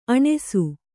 ♪ aṇesu